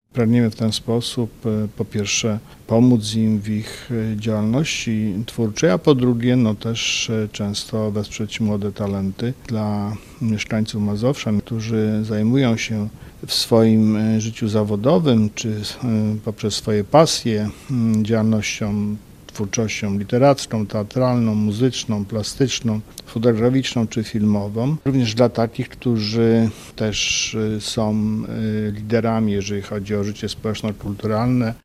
Chcemy wspierać młode talenty- zachęca do udziału w konkursie, marszałek Adam Struzik: